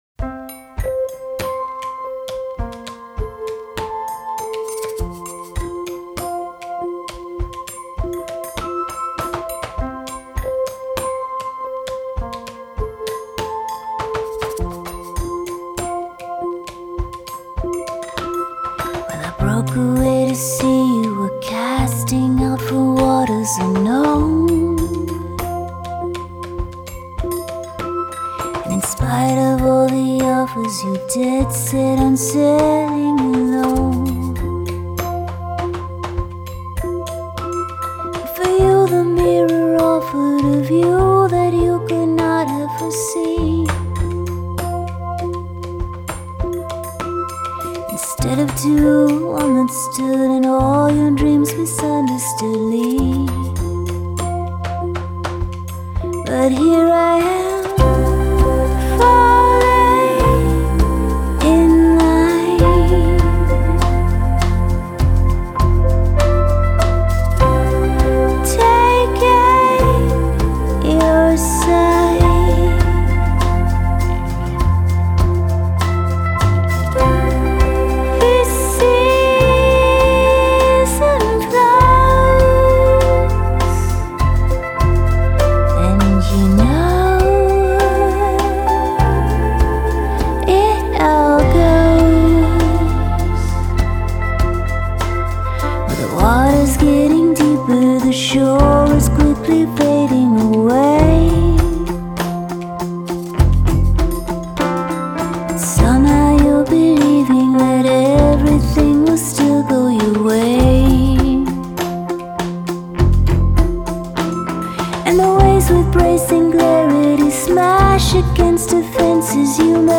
bass
guitar
drums